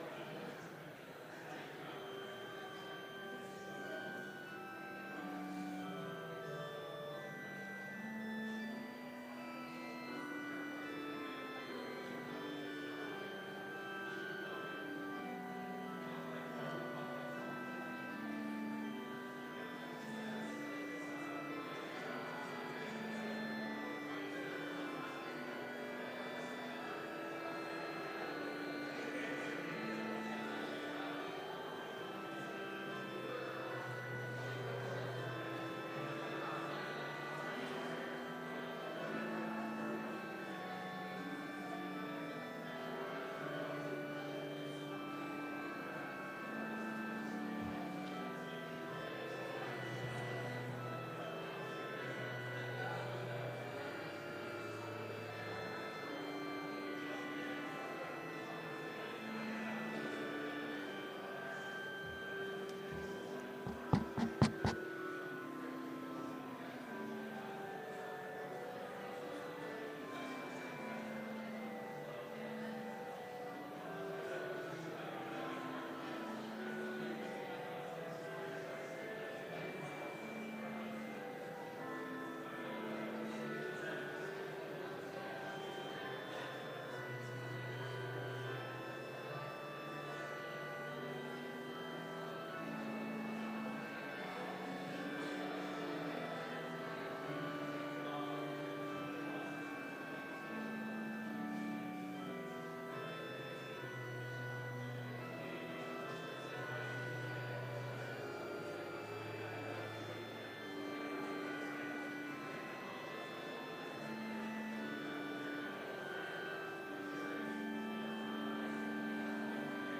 Complete service audio for Chapel - October 30, 2019
Complete Service